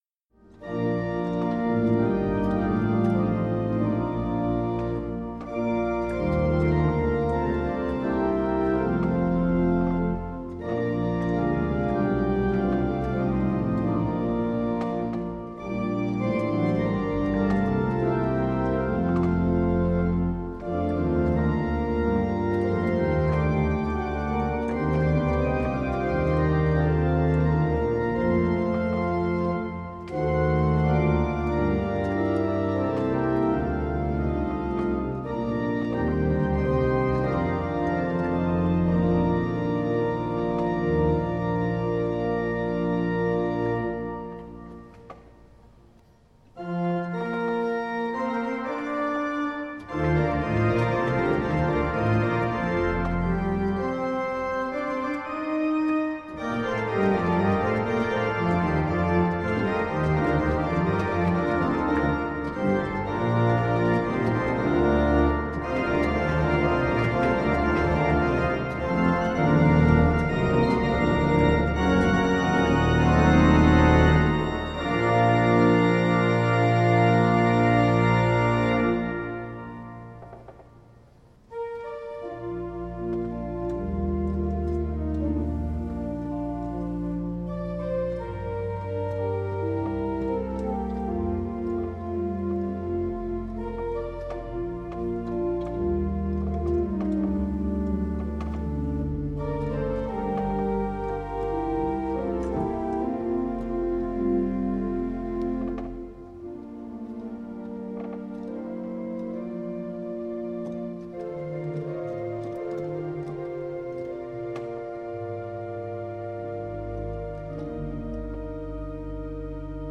Přerov, kostel sv. Vavřince - varhany
Improvizace na píseň
Další zajímavostí dispozice je dvouřadý Principál 8' v hlavním stroji a nadprůměrný rozsah manuálů (C-a3).